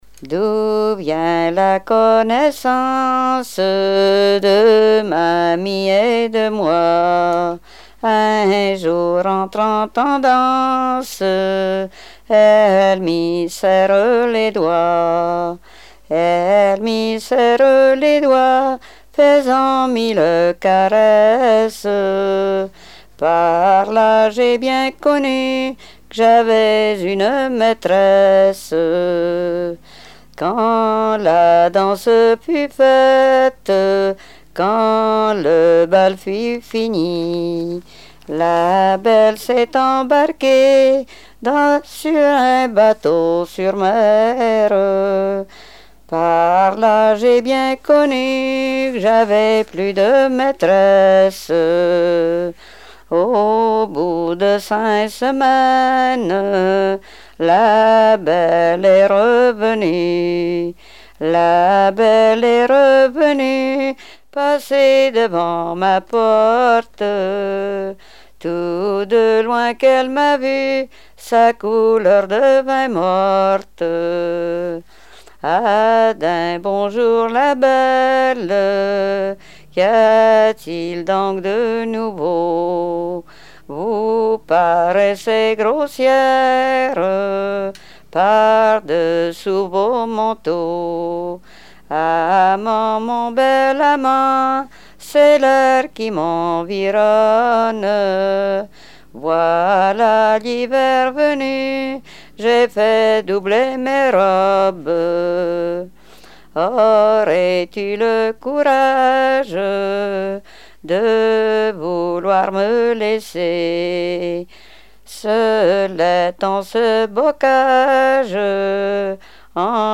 Genre strophique
Répertoire de chansons traditionnelles et populaires
Pièce musicale inédite